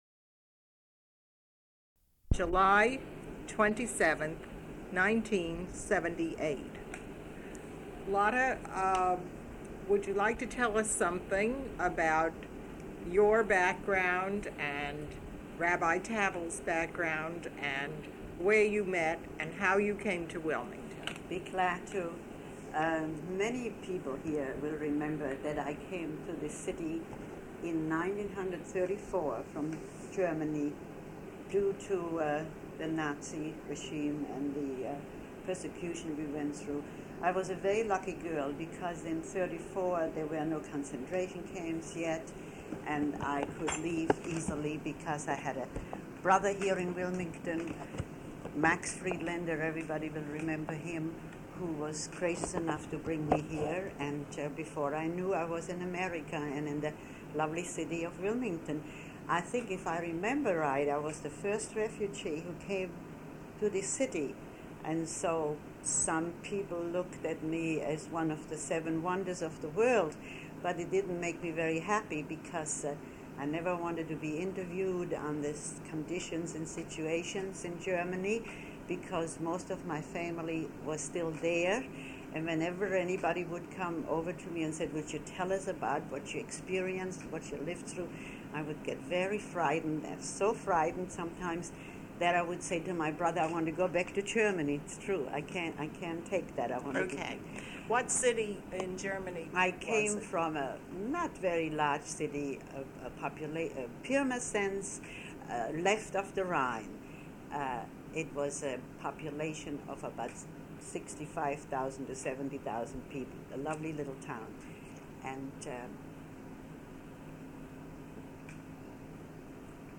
Oral History Collection